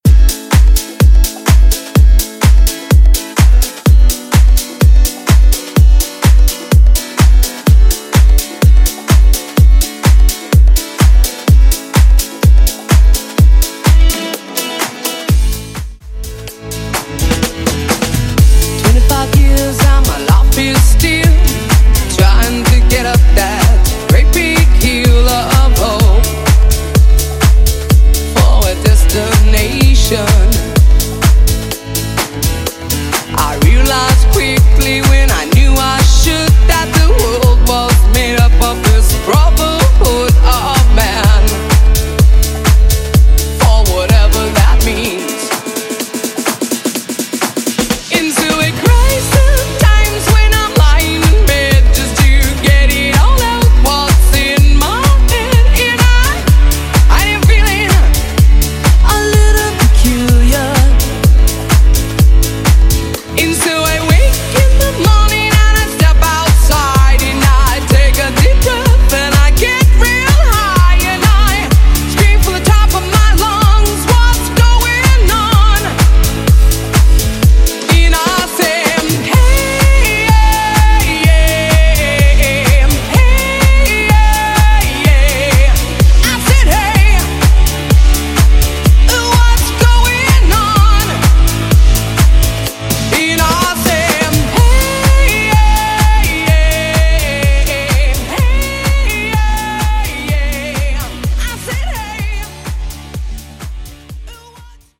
Genre: DANCE
Clean BPM: 123 Time